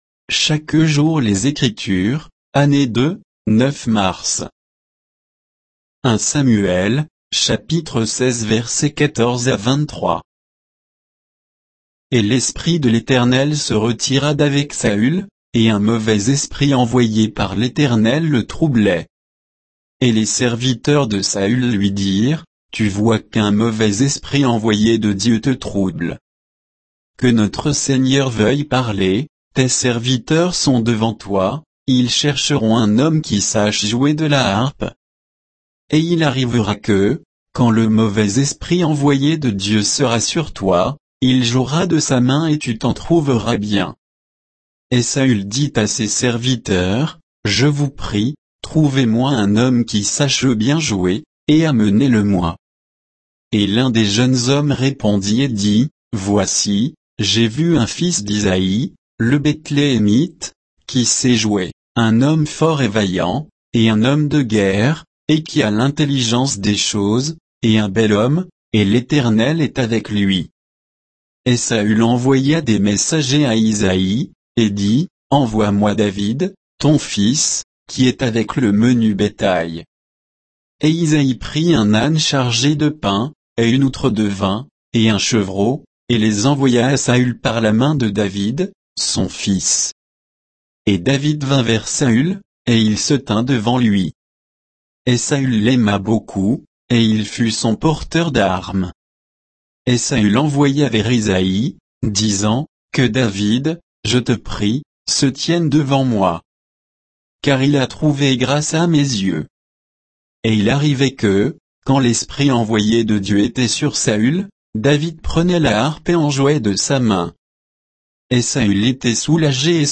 Méditation quoditienne de Chaque jour les Écritures sur 1 Samuel 16